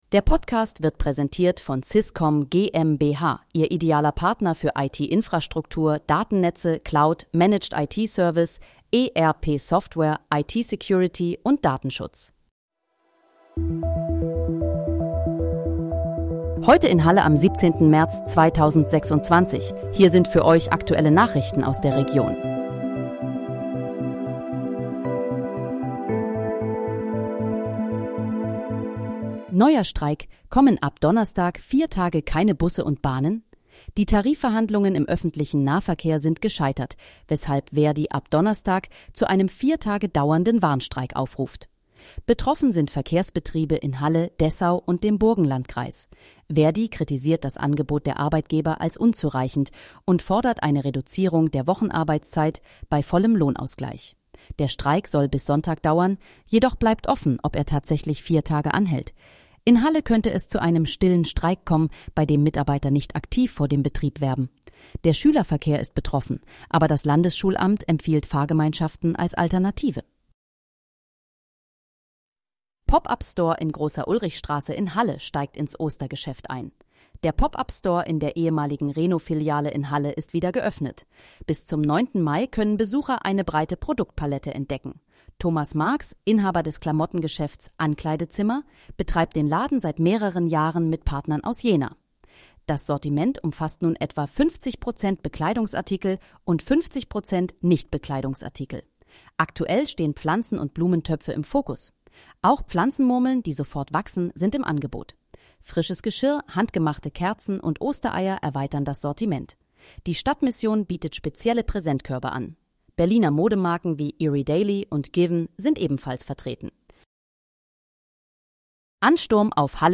Heute in, Halle: Aktuelle Nachrichten vom 17.03.2026, erstellt mit KI-Unterstützung
Nachrichten